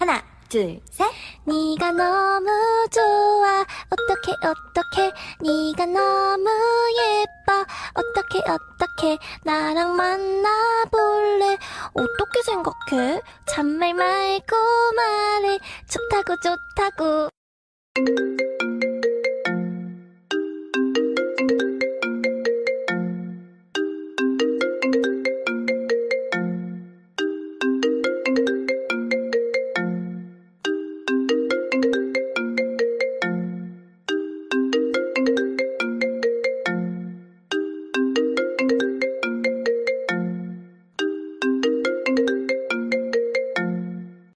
Genre: Nada dering panggilan